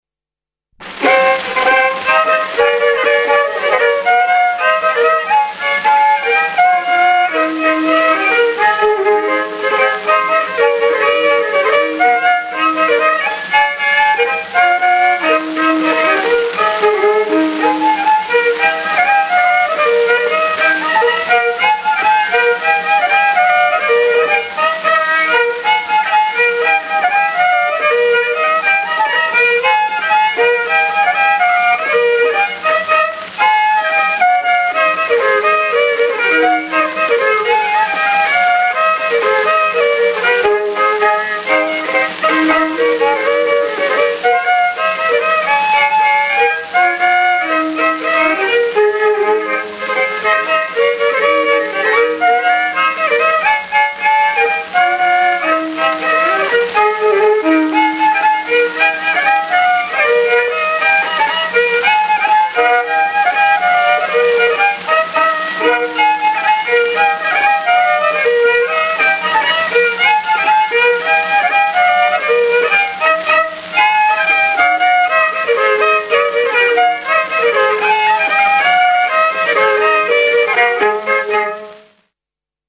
I viiul
II viiul
15 Polka.mp3